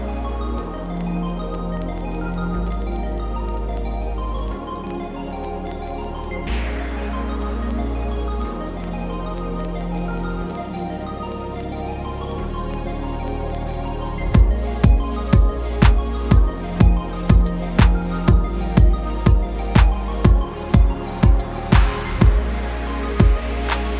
no Backing Vocals Dance 4:16 Buy £1.50